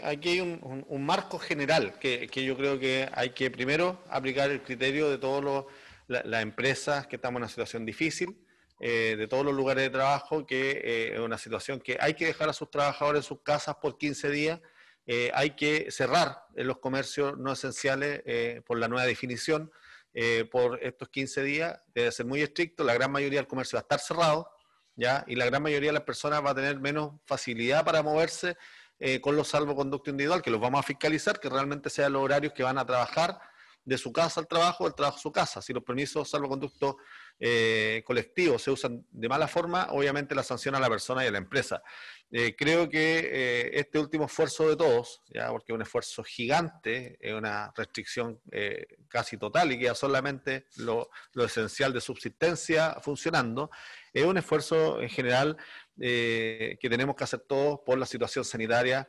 Desde la Región del Biobío, el seremi de Salud, Héctor Muñoz, entregó más detalles acerca del funcionamiento estas medidas y llamó a los empleadores a acatar y sumarse a lo que definió como «un esfuerzo gigante».